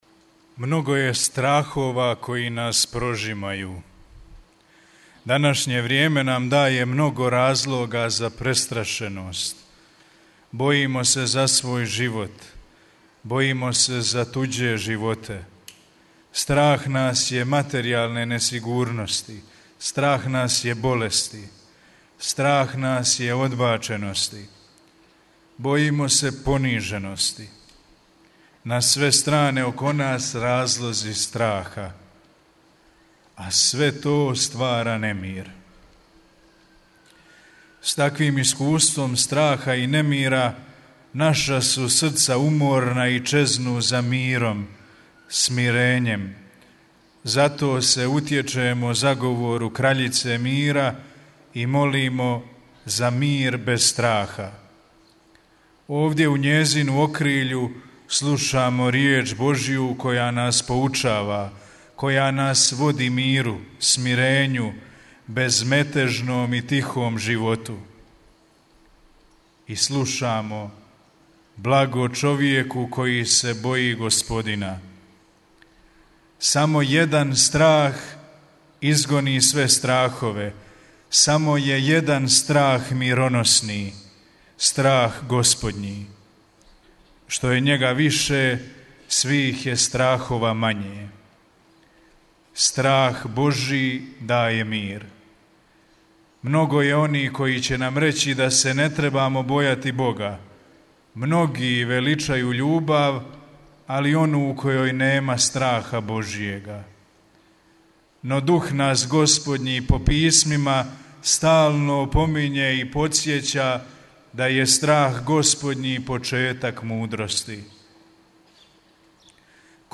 Na početku svoje homilije osvrnuo se na Psalam 112, koji kaže: ‘Blago čovjeku koji se boji Gospodina!’